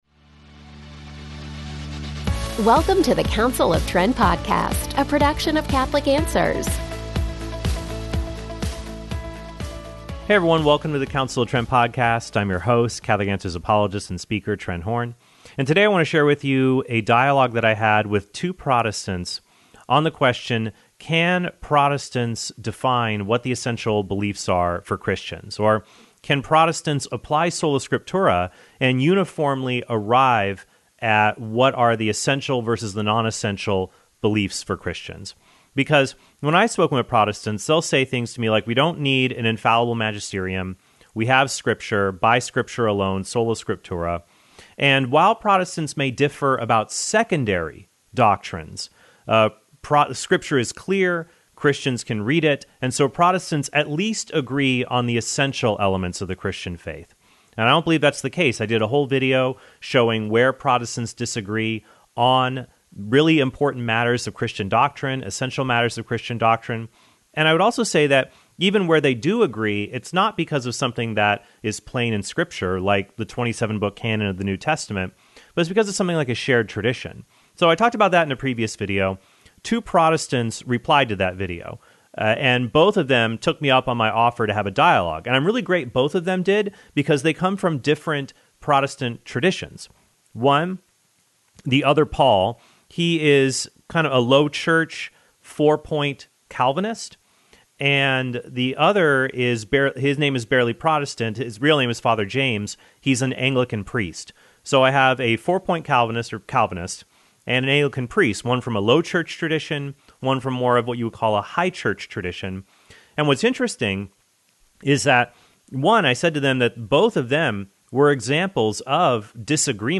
DIALOGUE: Can Protestants Agree on Essential Doctrine?